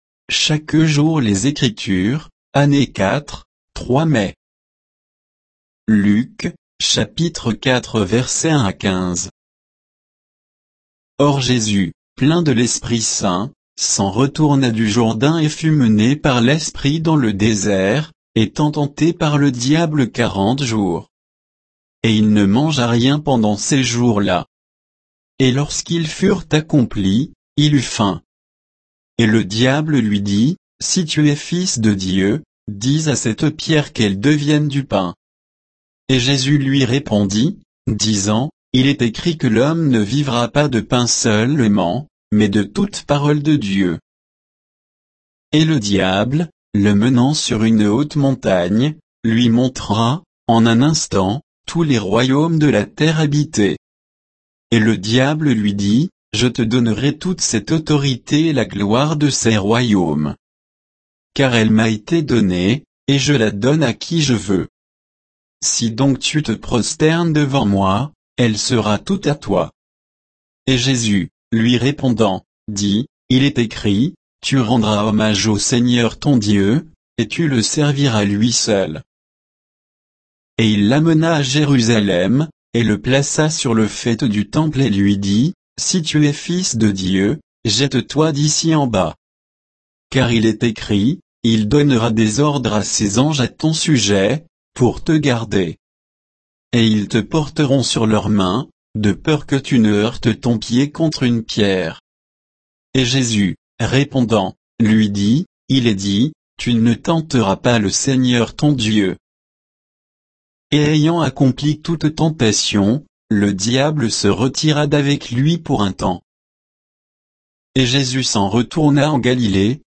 Méditation quoditienne de Chaque jour les Écritures sur Luc 4